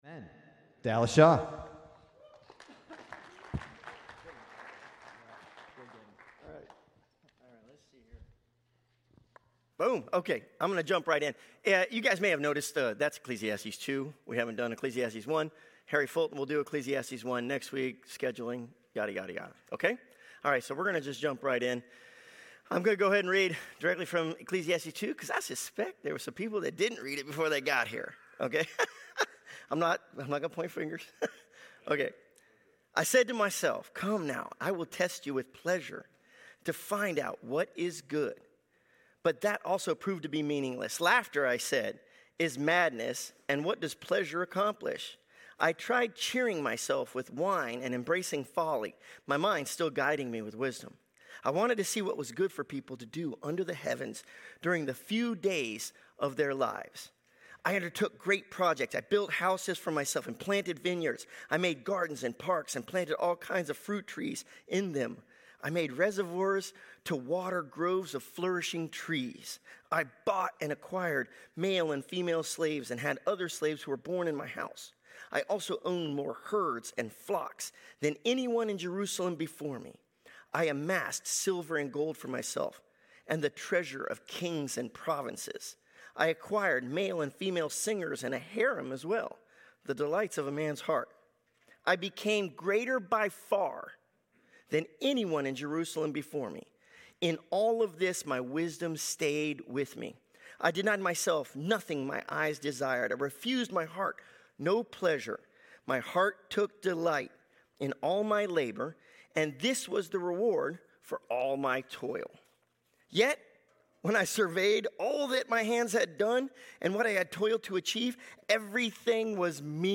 2026 Burke Community Church Lesson